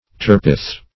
turpeth - definition of turpeth - synonyms, pronunciation, spelling from Free Dictionary
Turpeth \Tur"peth\, n. [NL. turpethum, fr. Per. tirbid a